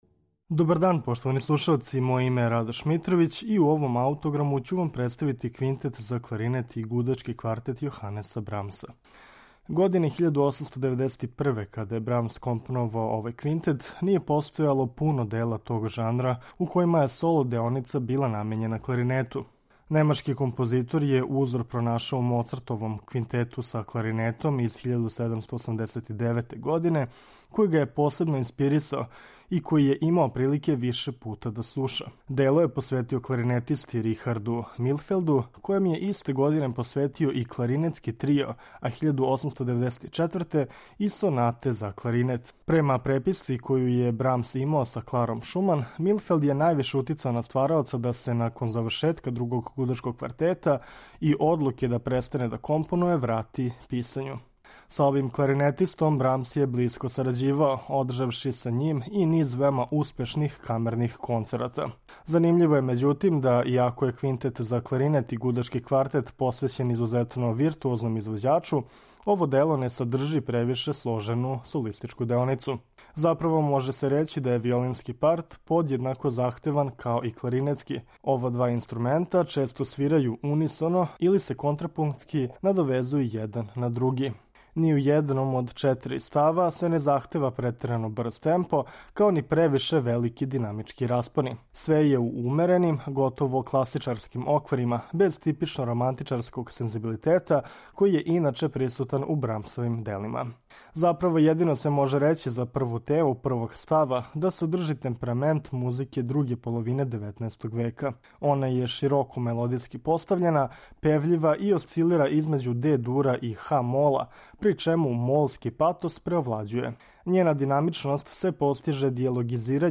Квинтет за кларинет и гудачки квартет Јоханеса Брамса написан је 1891. године и инспирисан је Моцартовим квинтетом из 1789. године.
У овој композицији виолински парт је махом равноправан са кларинетским; ова два инструмента се константно допуњују и надовезују током целокупног дела, стварајући богату звучну слику.